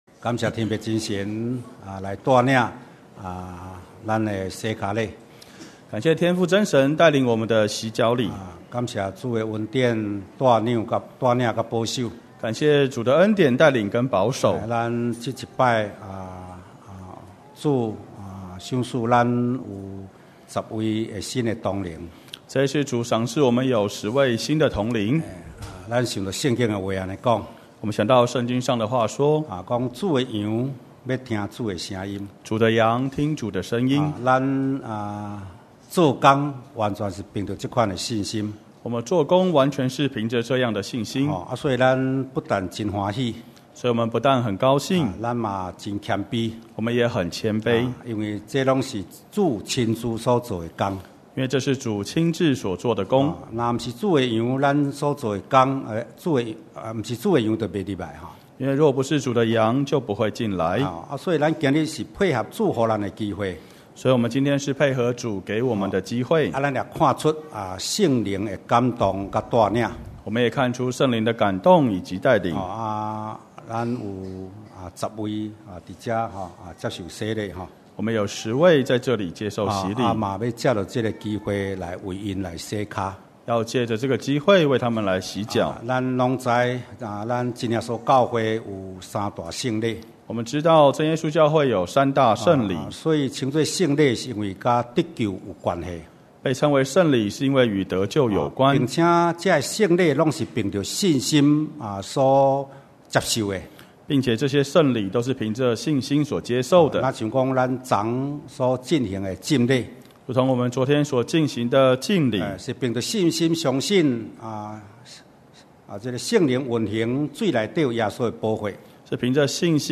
2017年7月份講道錄音已全部上線